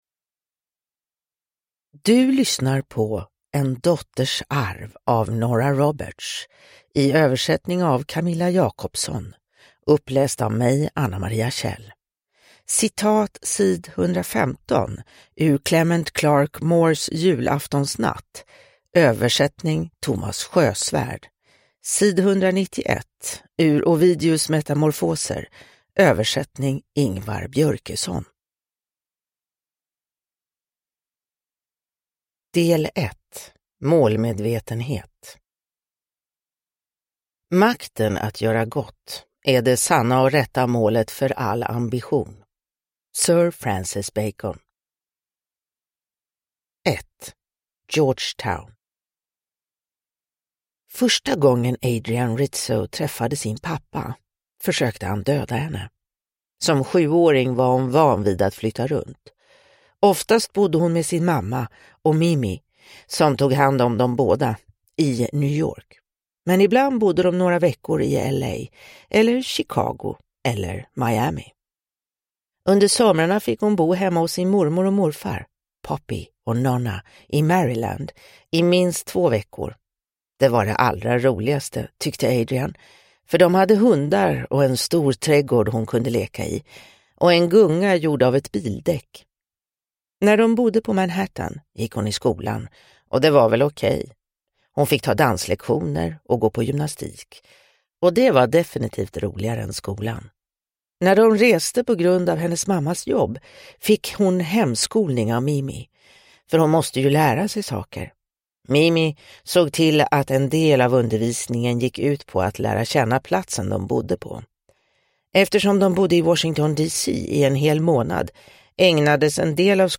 En dotters arv – Ljudbok – Laddas ner